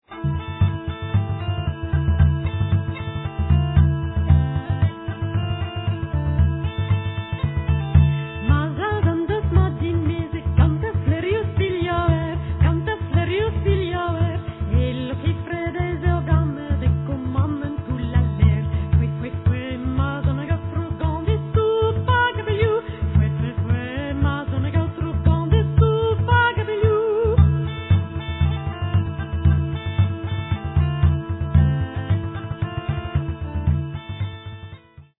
uilleann pipes, penny whistle
tenor sax, clarinets
fiddle
French and Breton folk songs